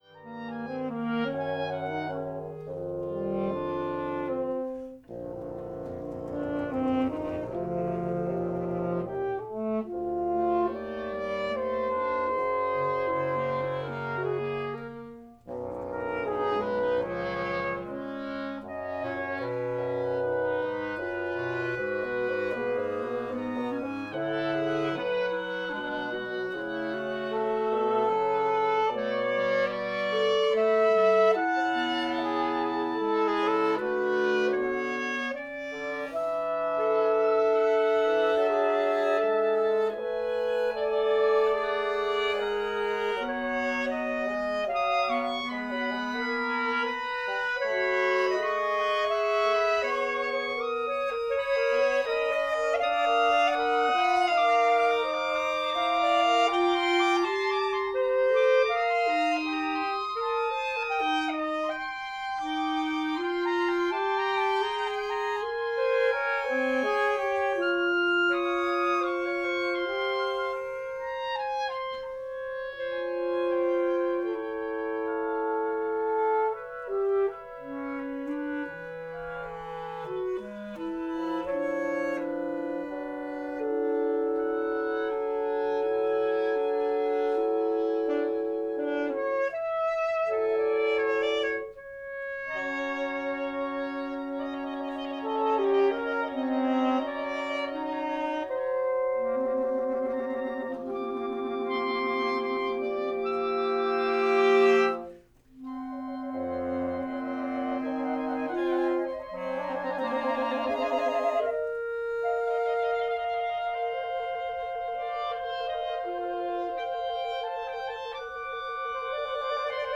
Oboe
Clarinet in Bb
Alto Saxophone
Bass Clarinet in Bb
Bassoon